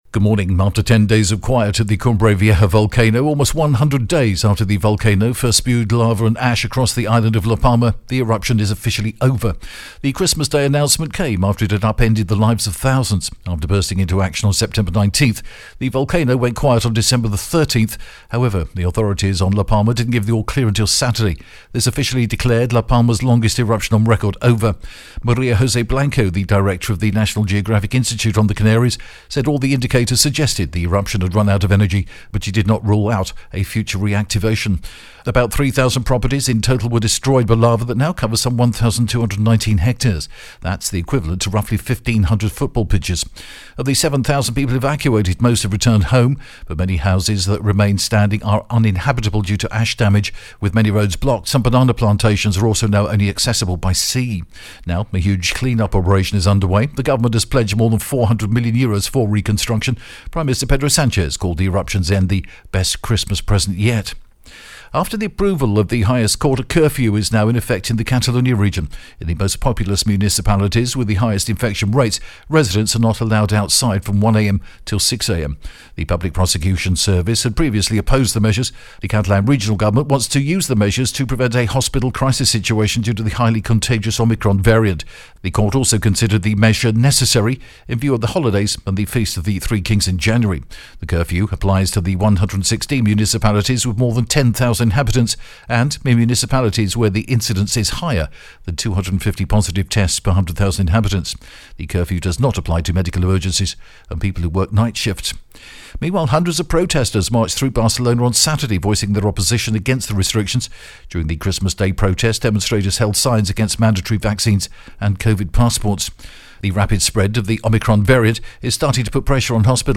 The latest Spanish news headlines in English: 27th December 2021 AM